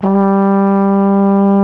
TENORHRN G 1.wav